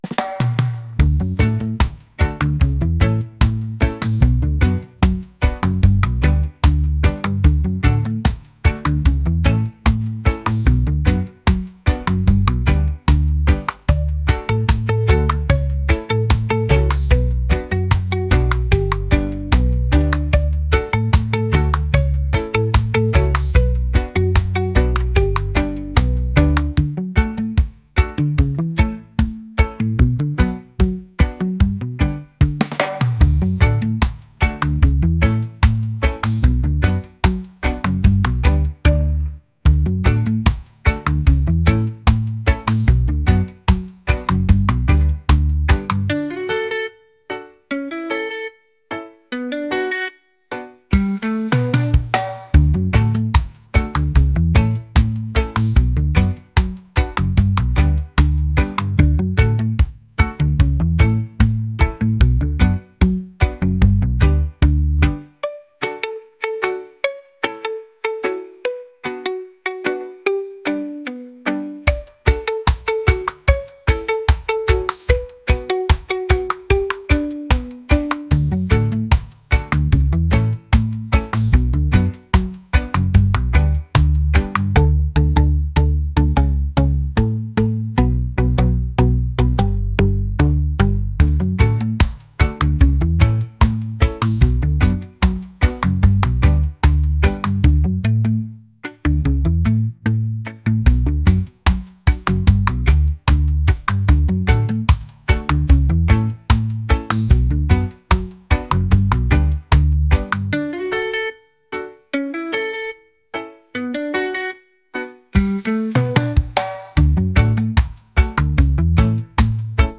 reggae | pop | funk